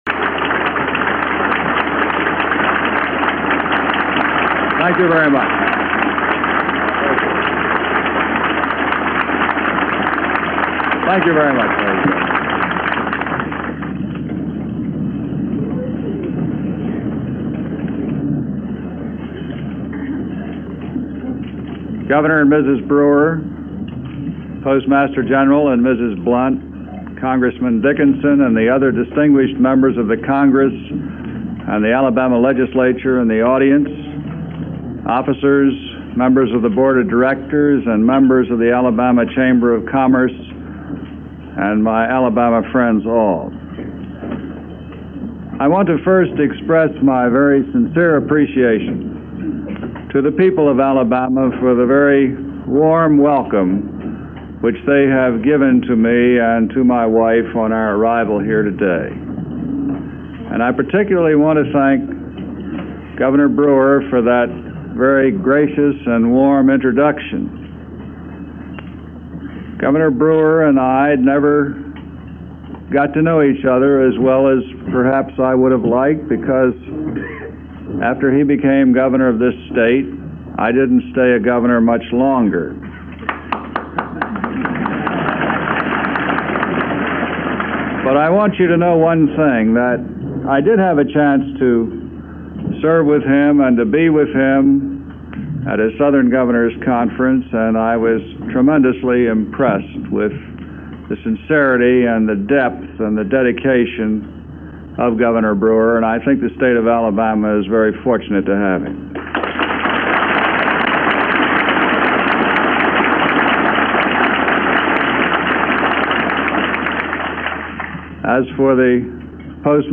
– Vice-President Spiro Agnew – Address in Montgomery Alabama – Nov. 10, 1969 –